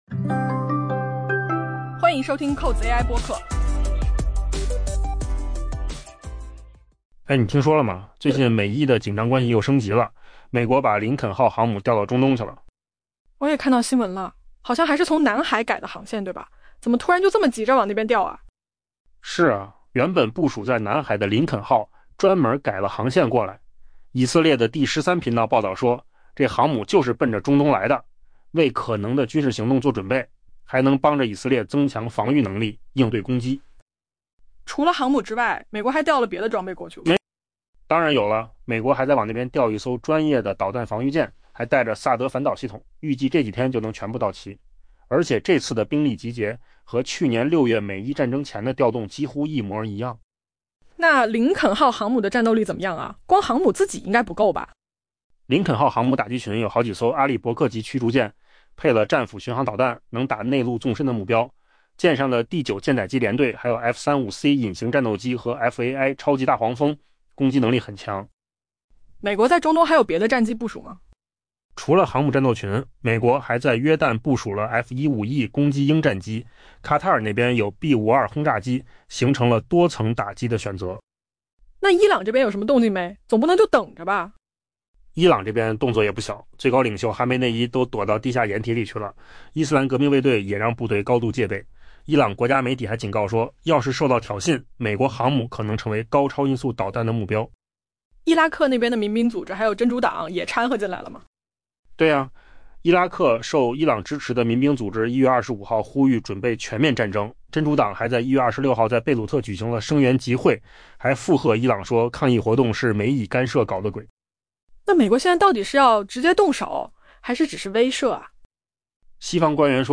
AI 播客：换个方式听新闻 下载 mp3 音频由扣子空间生成 据外媒报道，随着与伊朗的紧张关系达到沸点，美国最大的航空母舰之一已被调遣至伊朗的打击范围内。